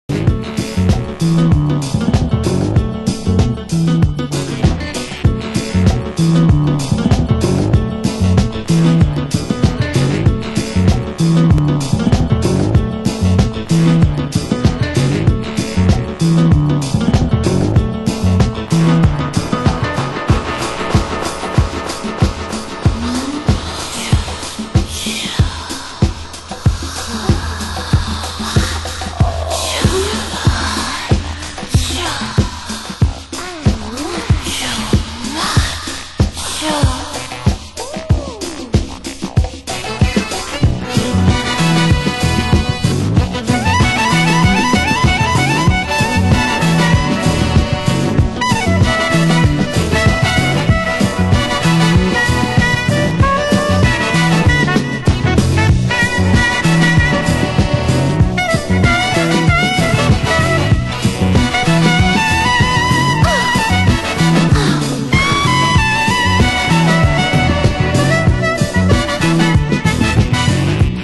★DISOCDUB NUHOUSE